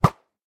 bow.ogg